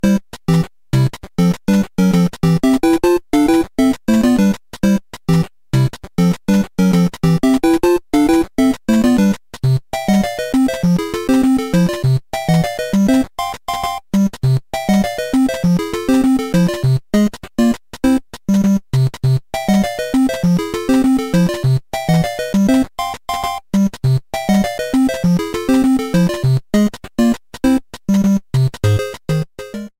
Started partway through the track and fadeout